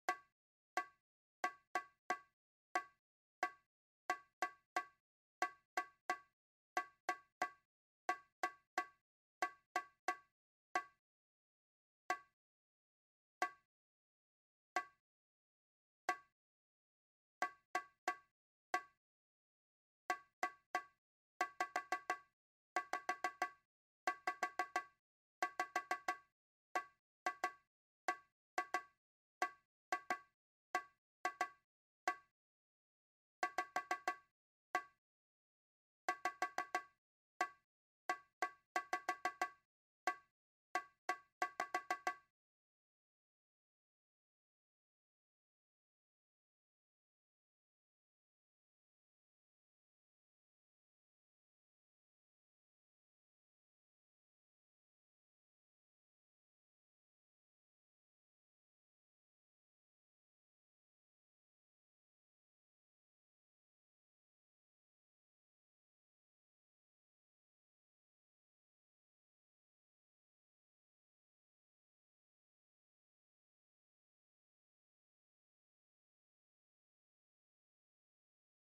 RYTHMES:
Avec le pdf et et le fichier mp3, écoute le rythme et répète-le par la suite: